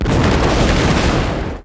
ZombieSkill_SFX
sfx_skill 12_3.wav